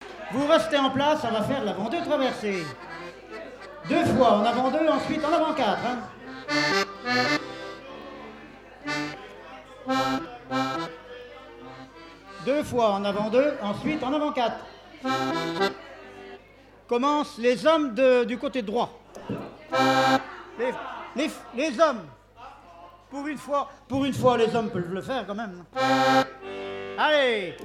Fête de l'accordéon
Catégorie Témoignage